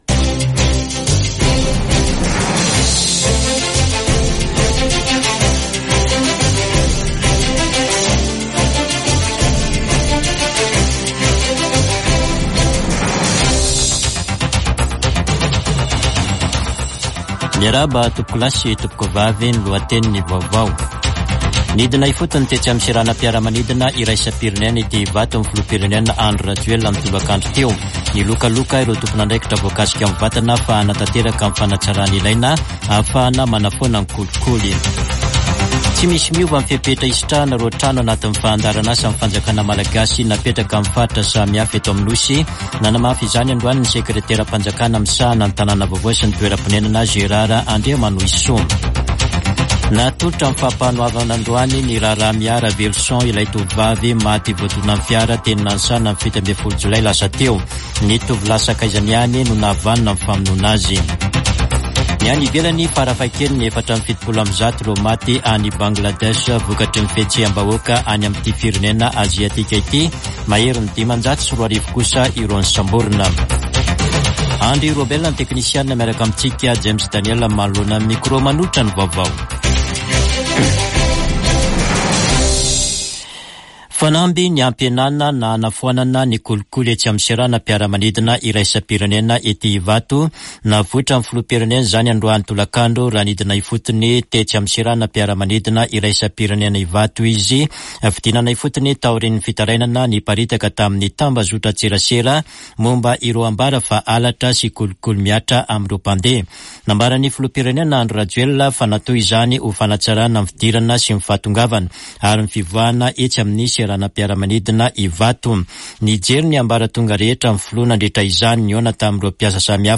[Vaovao hariva] Talata 23 jolay 2024